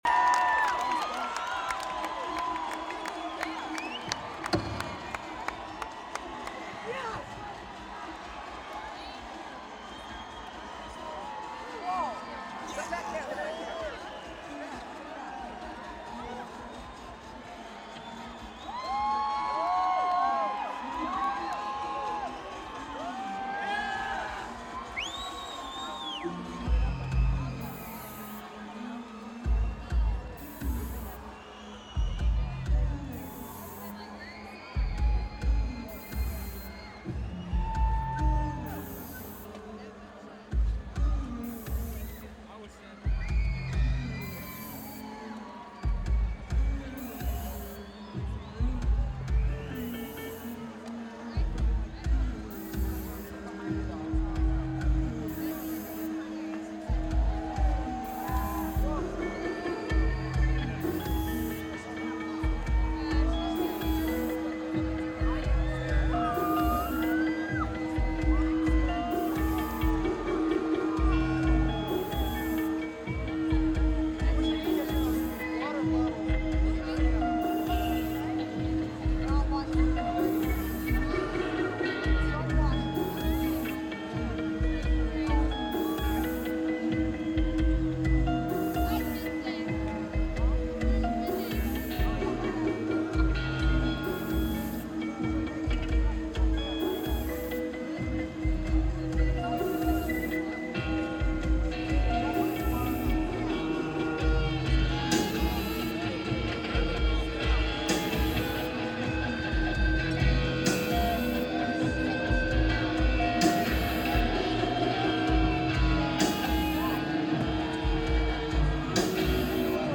Thomas & Mack Center
Lineage: Audio - AUD (Sony ECM-737 + Sony TCD-D7)